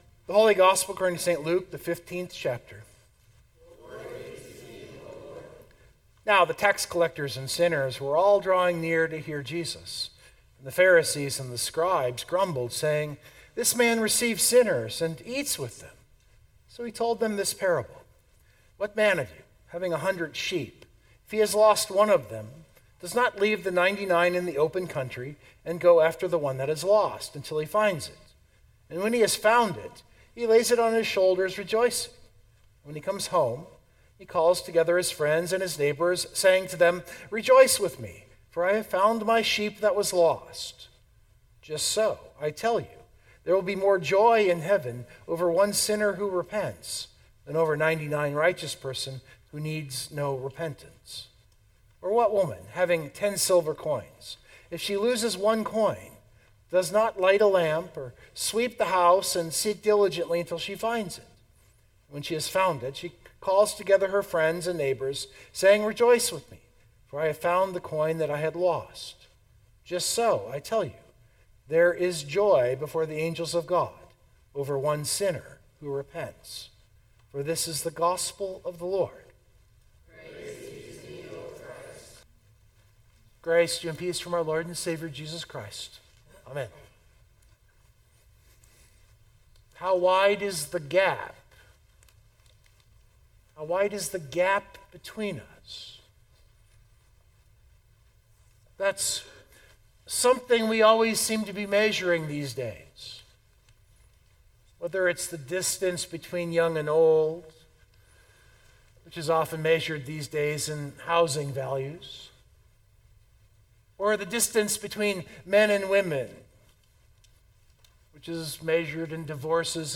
091425 Sermon Download Biblical Text: Luke 15:1-10 Again, I am always amazed at how the lectionary serves up a perfect basis to preach to the day.